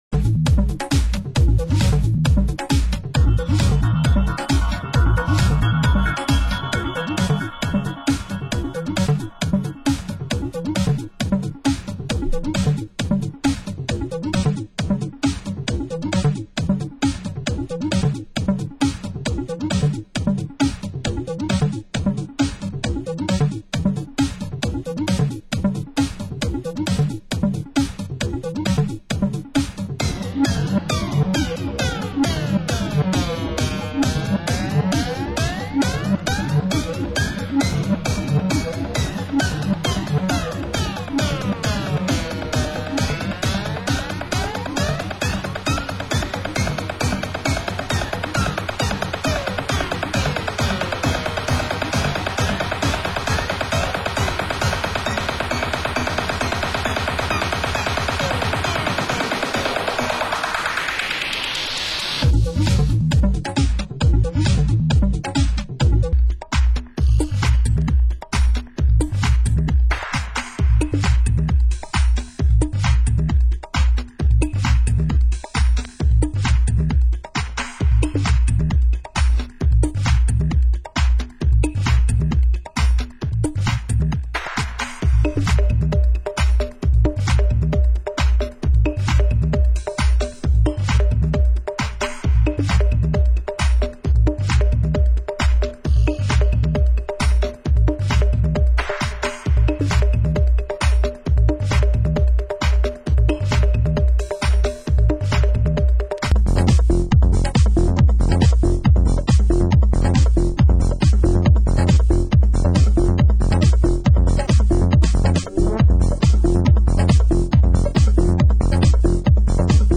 Genre: Minimal